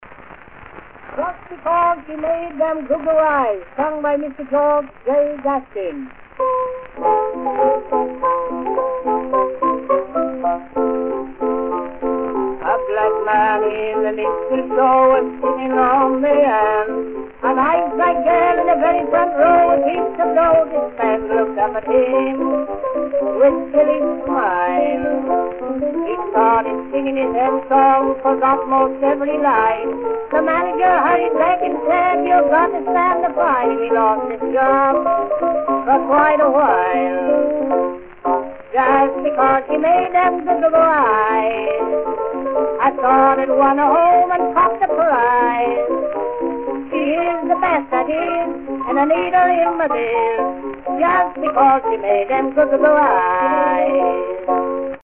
Запись звука производилась на восковой валик тонкой металлической иглой.